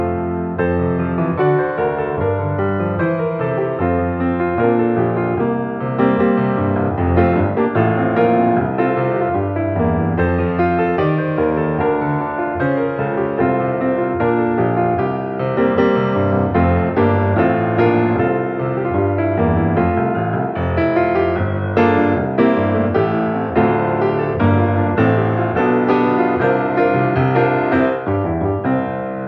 arranged for piano and light instrumentals